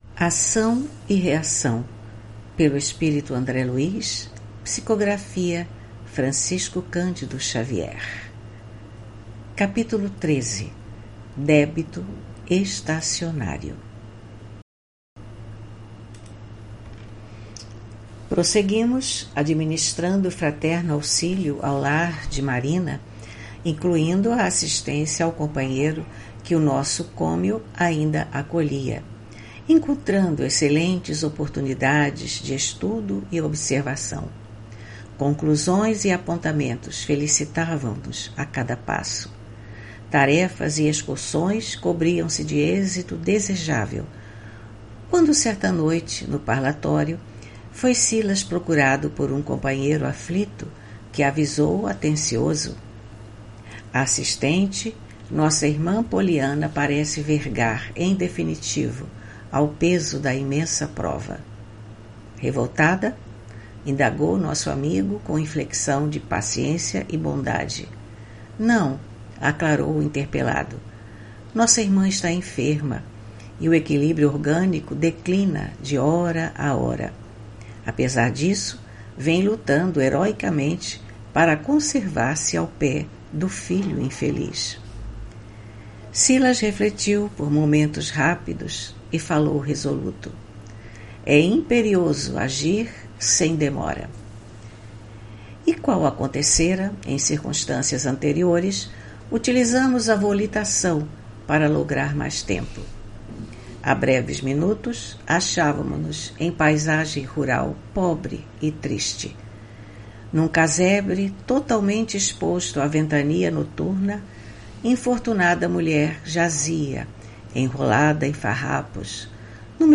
Leitura do livro: Ação e reação, autoria do espírito André Luiz, psicografia de Francisco Candido Xavier.